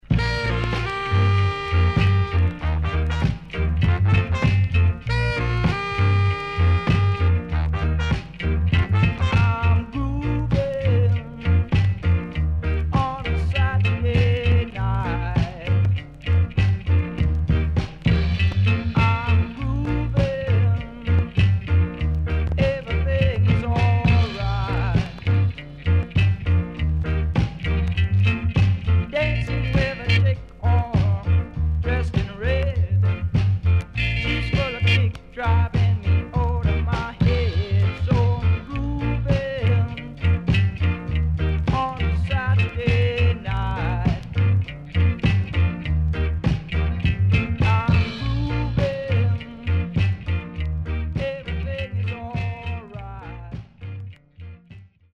HOME > Back Order [VINTAGE 7inch]  >  ROCKSTEADY
SIDE A:所々チリノイズがあり、少しプチパチノイズ入ります。